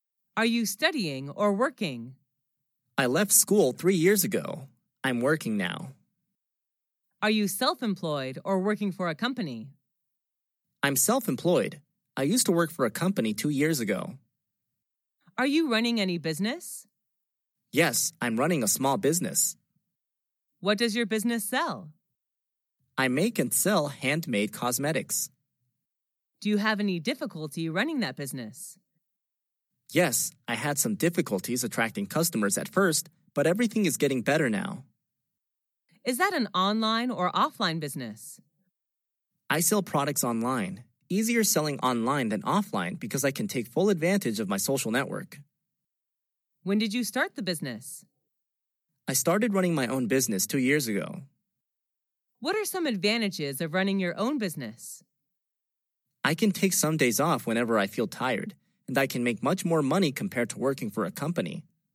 Sách nói | QA-57